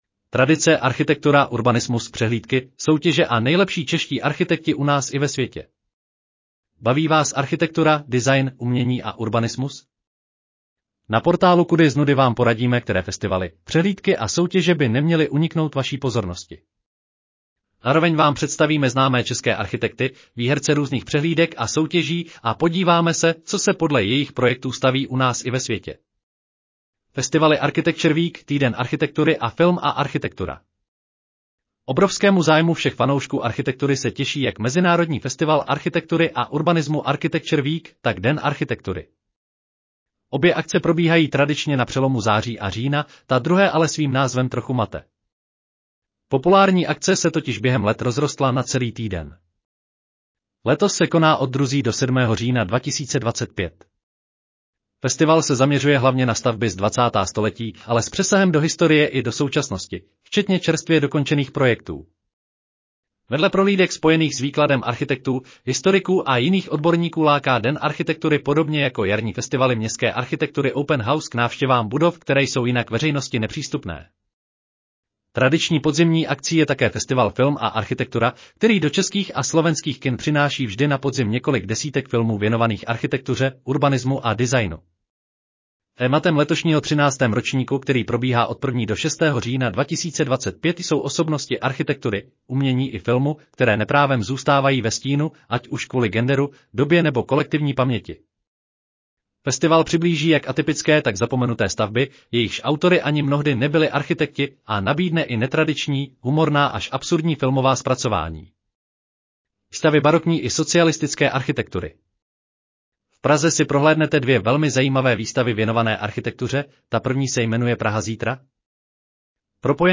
Audio verze článku Tradice: architektura, urbanismus, přehlídky, soutěže a nejlepší čeští architekti u nás i ve světě